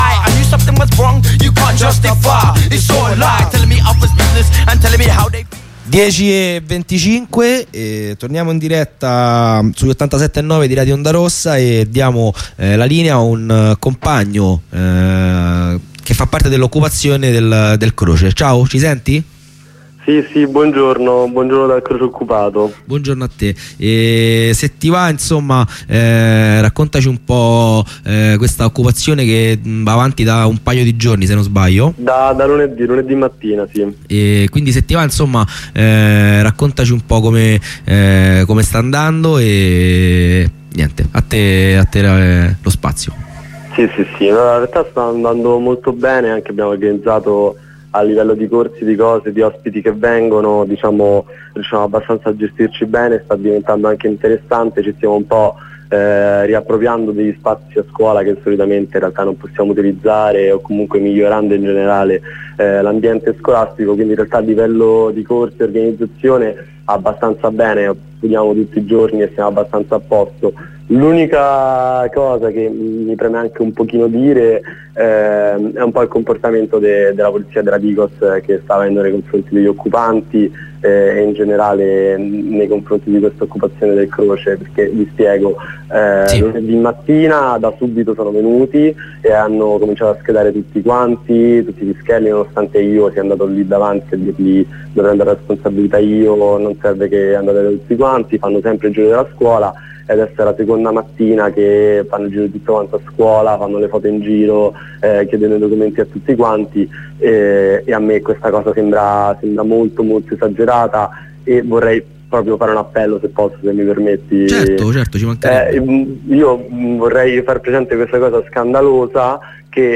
corteo nazionale per la palestina
Corrispondenza con una compagna di Milano dal corteo